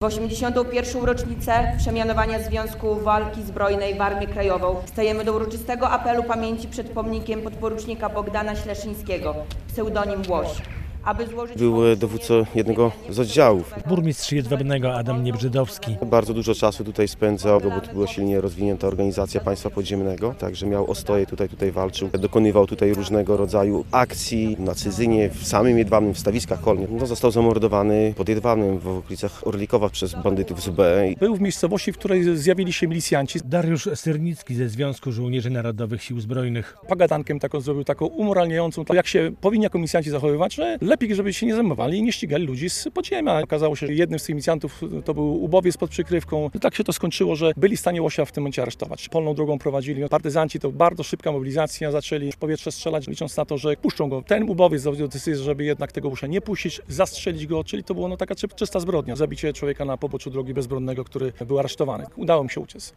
Z wojskową asystą w Jedwabnem odsłonięto pomnik żołnierzy podziemia niepodległościowego i jednego z jego lokalnych dowódców.